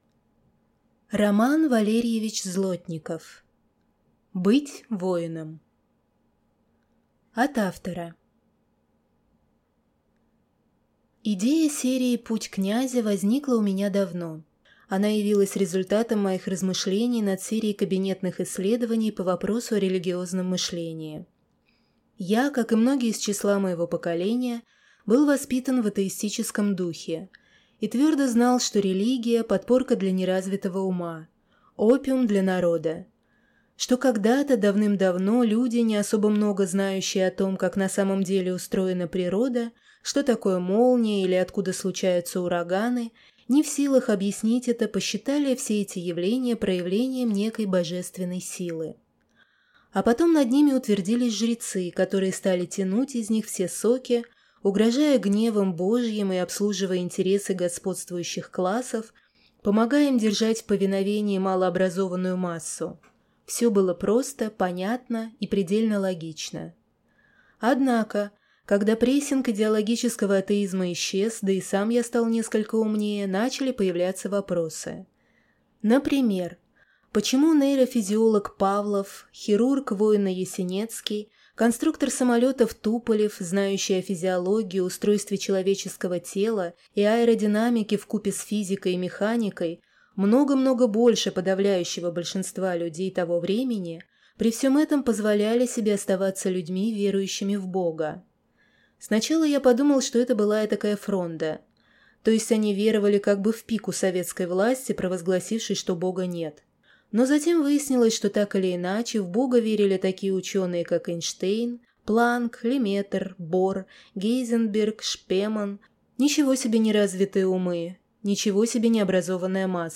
Аудиокнига Быть воином | Библиотека аудиокниг
Прослушать и бесплатно скачать фрагмент аудиокниги